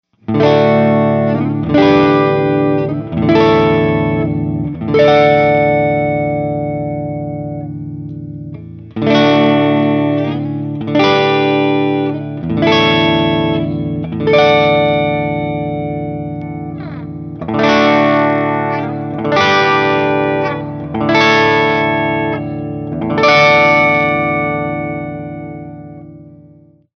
I also recorded some clean tones using a simulated Fender ‘65 Deluxe Reverb Amplifier. I cranked the volume up to 10 and put on a little reverb.
Clean Guitar Audio Sample